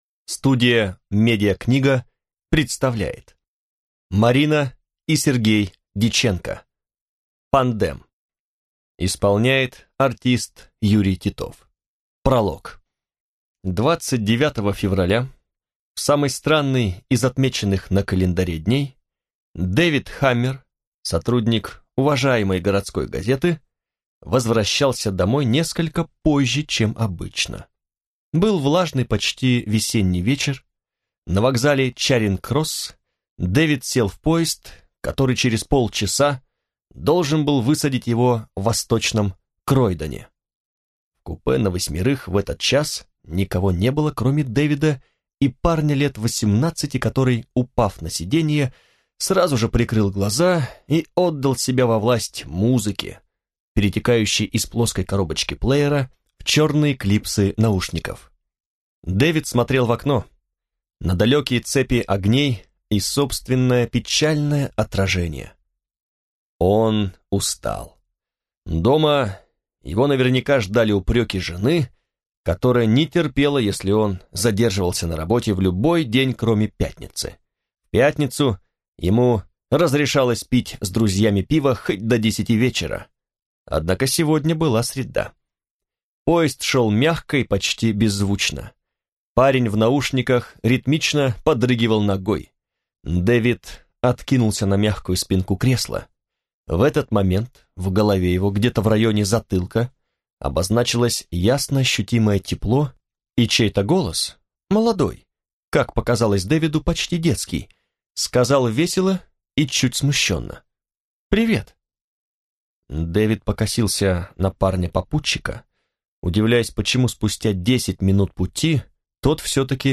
Аудиокнига Пандем | Библиотека аудиокниг